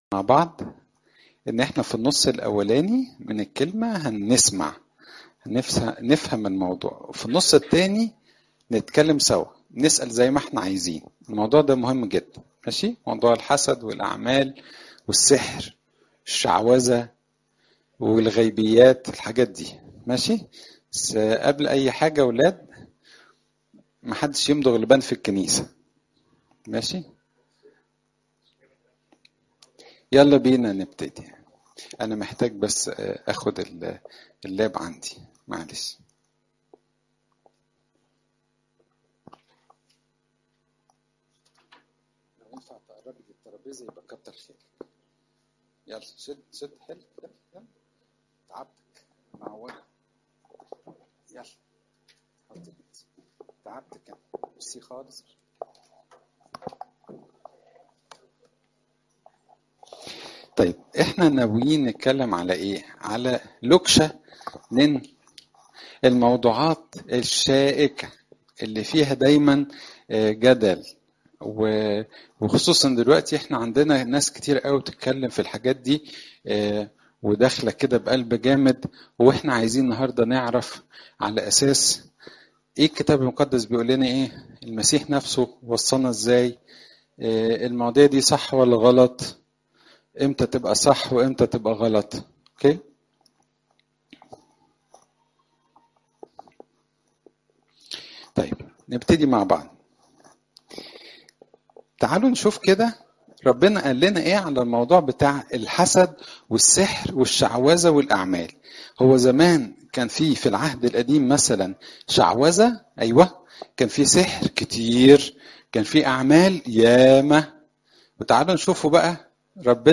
Seminar on envy and deeds
18443-seminar-on-envy-and-deeds